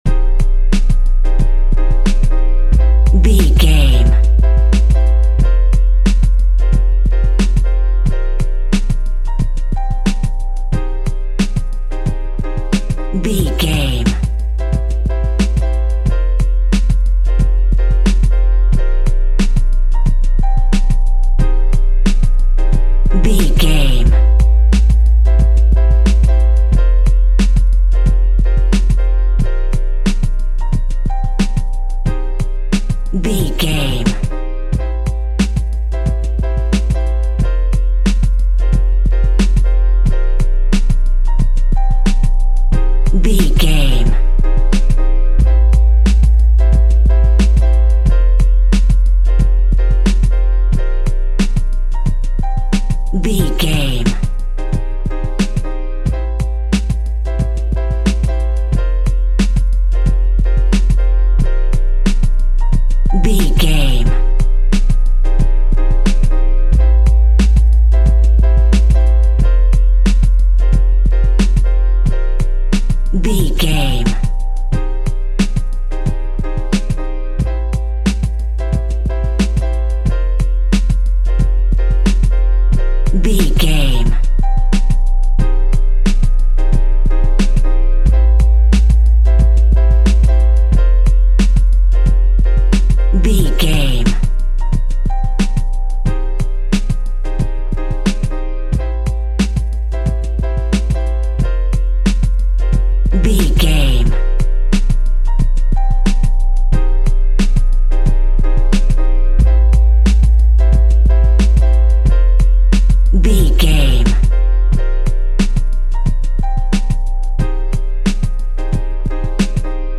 Aeolian/Minor
Funk
synth drums
synth leads
synth bass
electronics